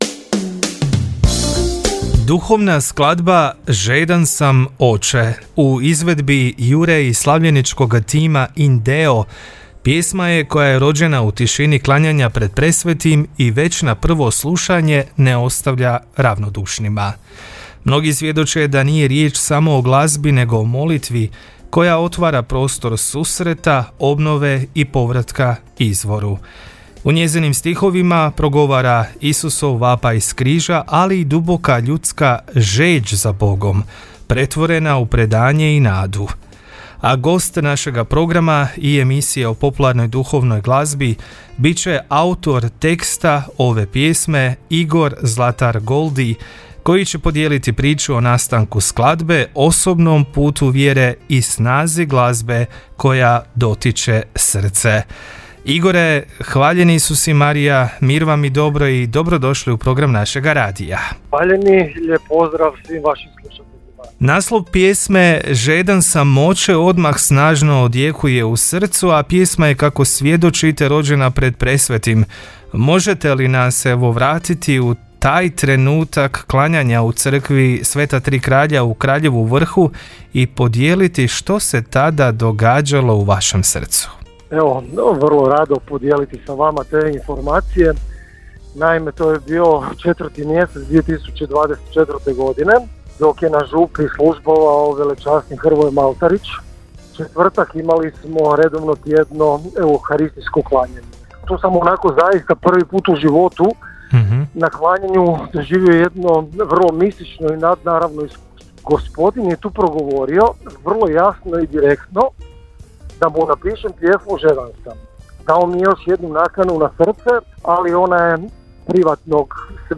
Gost emisije Popsacro Val, emisije o popularnoj duhovnoj glazbi